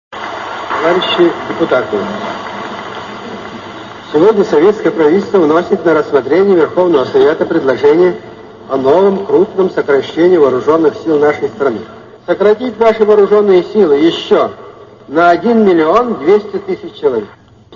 Исполняет: Н.С.Хрущев Исполнение 1959г.